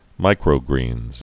(mīkrō-grēnz)